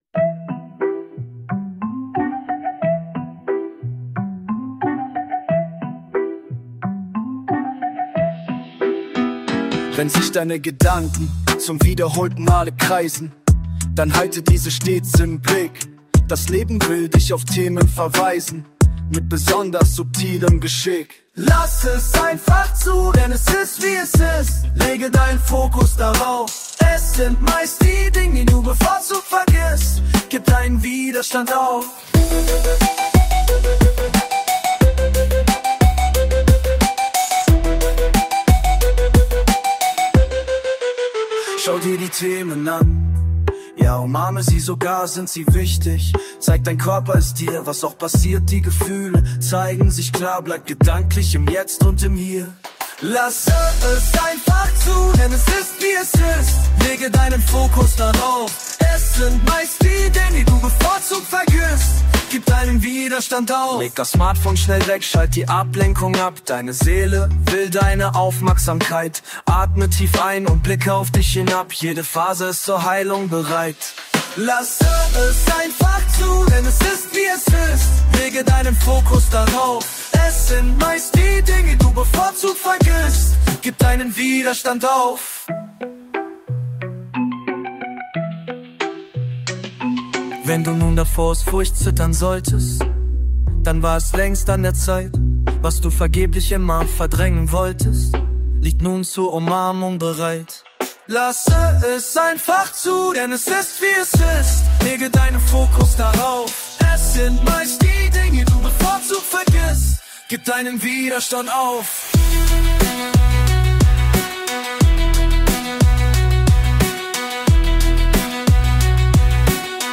East-Coast-Hip Hop